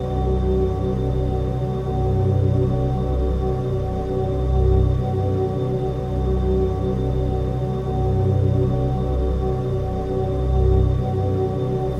music_layer_base.mp3